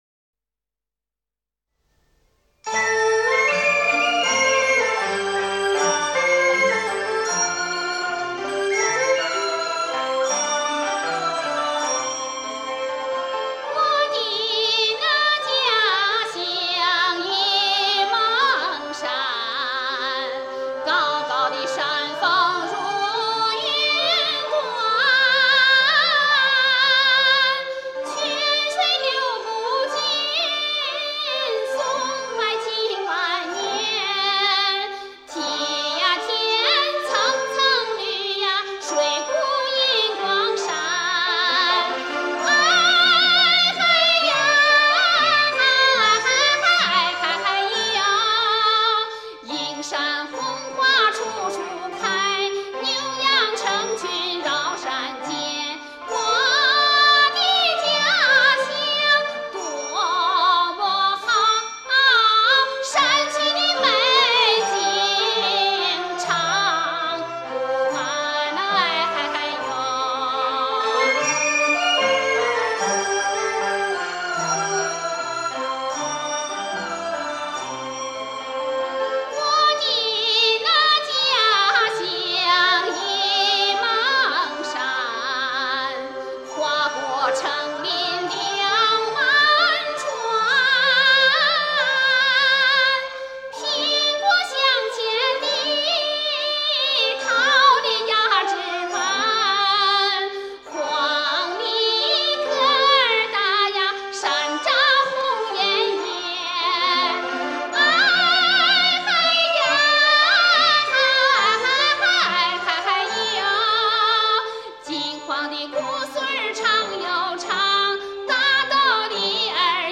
民族唱法的主要开拓者和奠基者